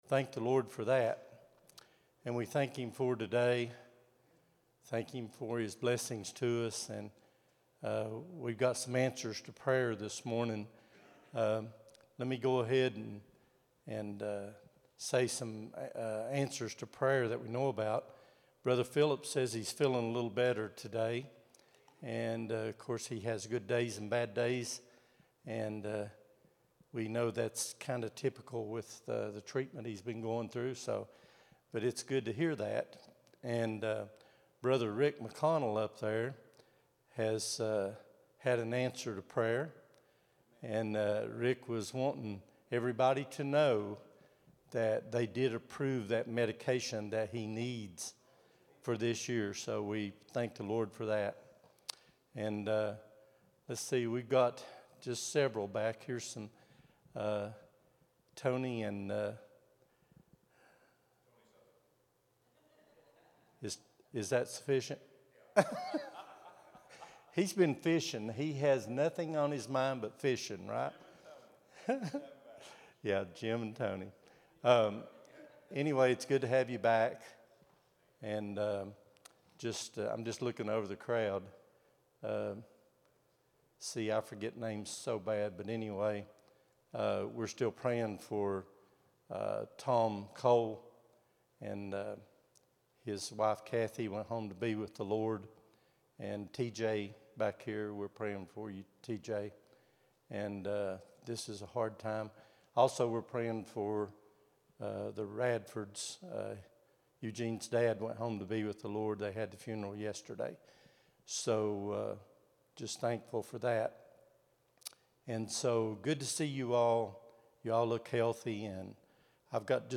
02-08-26 Sunday School | Buffalo Ridge Baptist Church